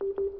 misslebeep3.wav